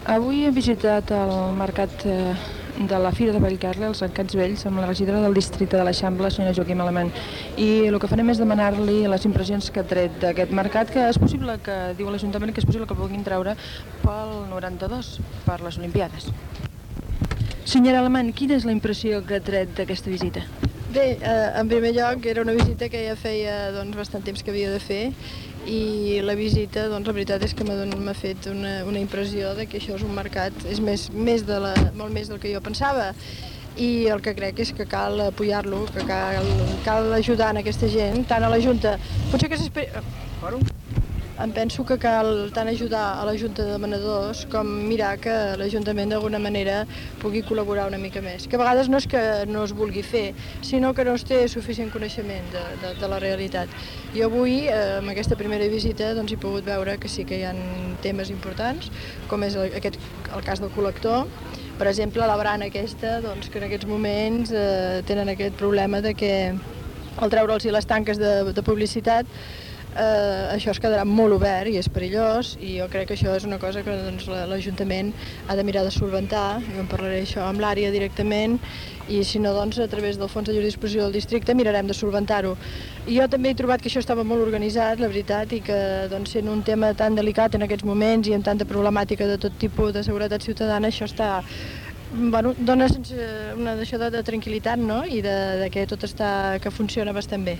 Mercat dels Encants vells de Barcelona, amb declaracions de Joaquima Alemany, presidenta del Districte de l'Eixample de Barcelona